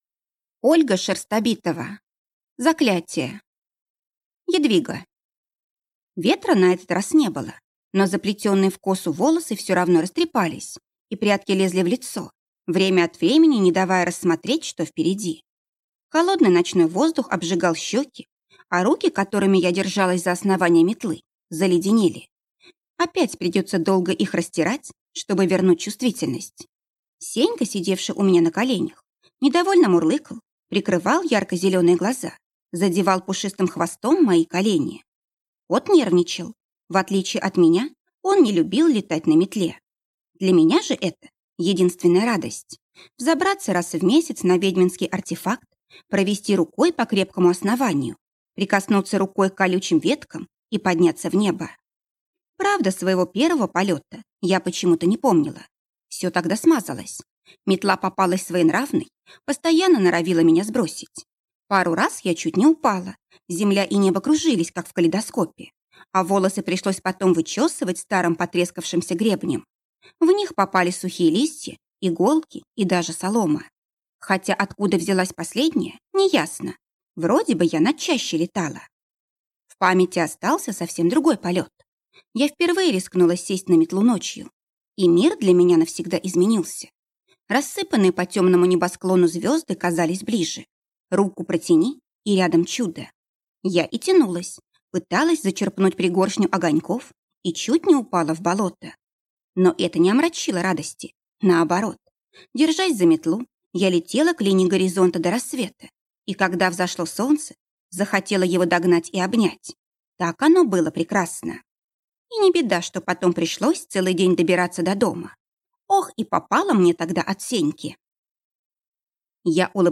Аудиокнига Заклятие | Библиотека аудиокниг